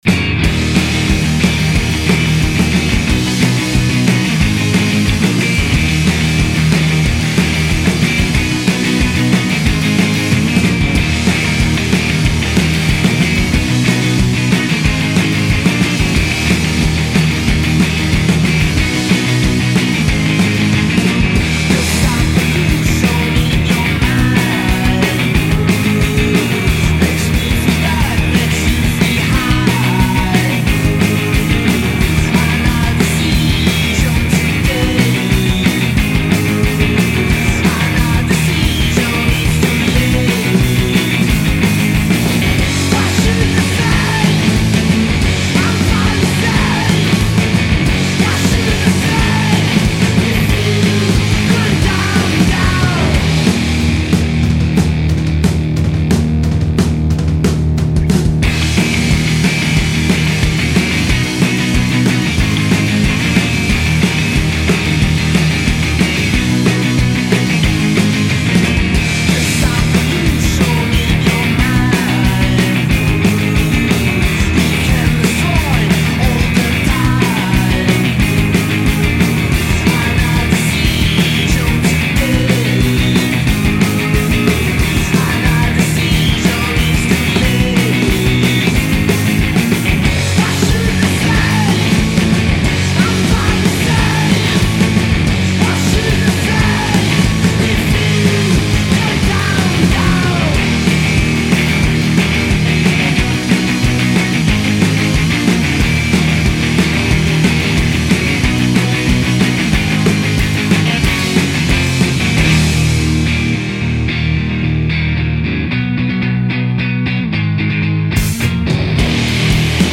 guitar/vocals
drum/vocals